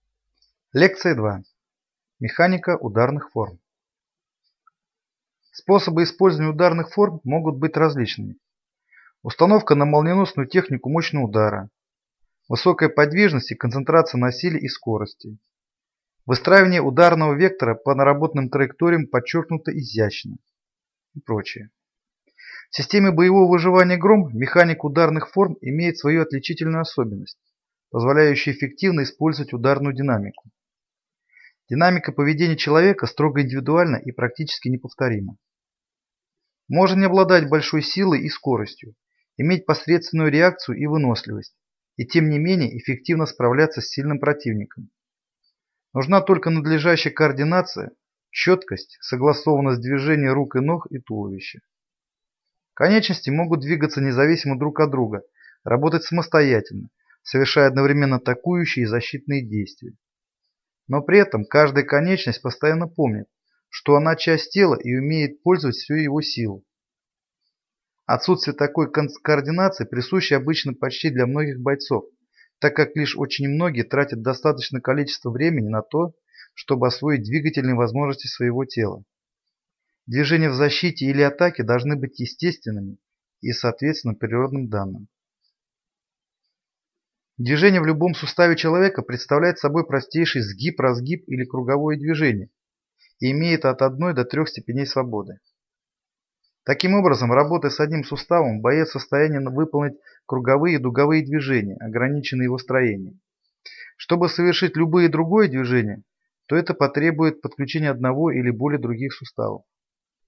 Аудиокнига Лекция 2. Механика ударных форм | Библиотека аудиокниг